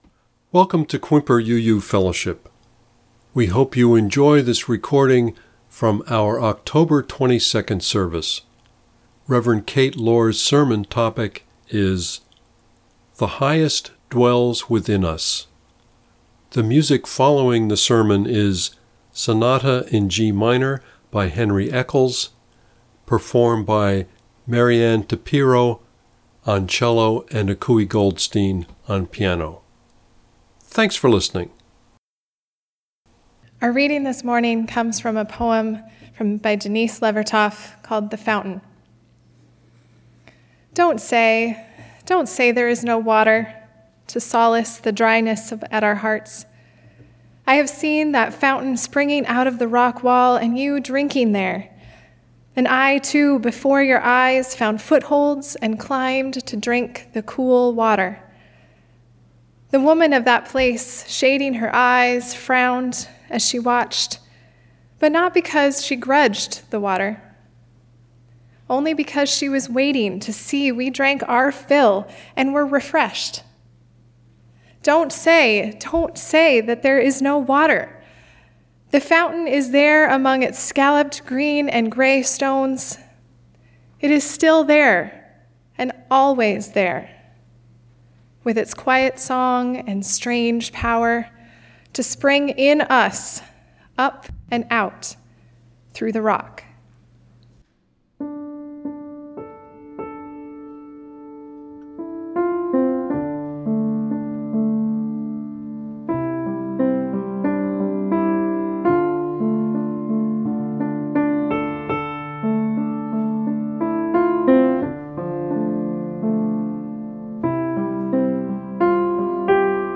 Click here to listen to the reading and sermon.